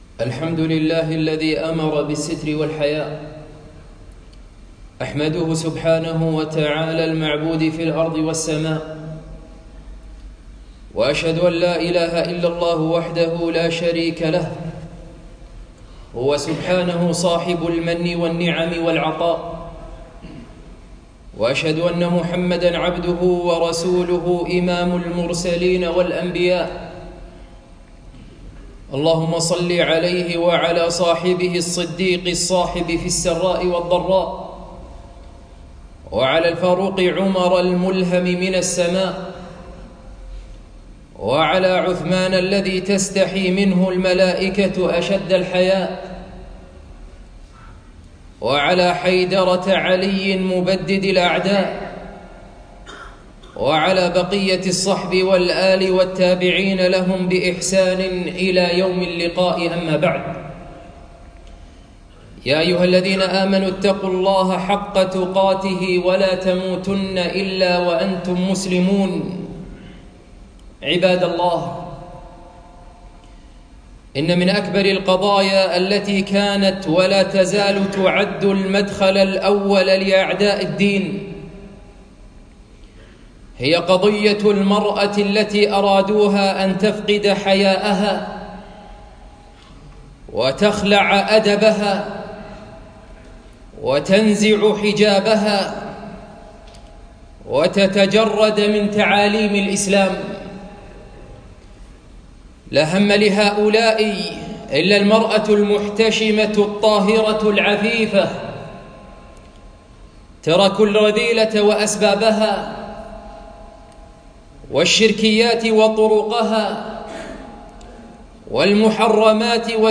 خطبة - حراسة الفضيلة